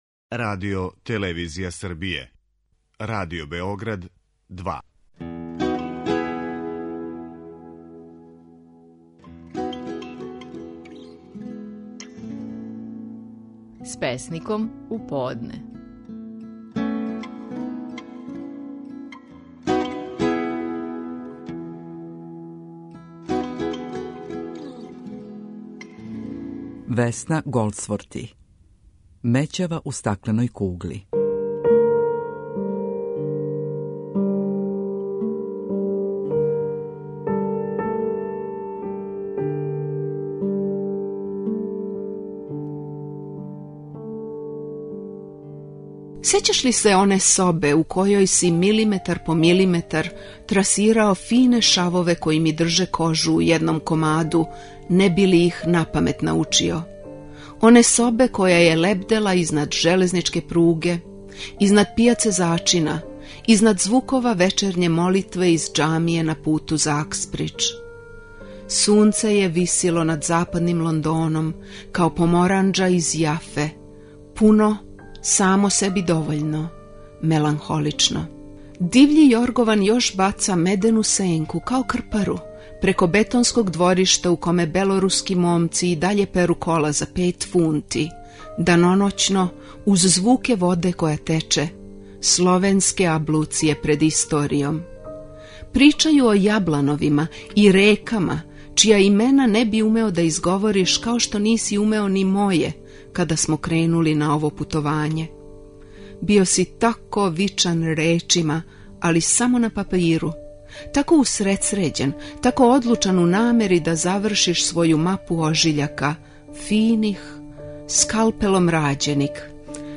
Стихови наших најпознатијих песника, у интерпретацији аутора.
Весна Голдсворти говори песму: „Мећава у стакленој кугли".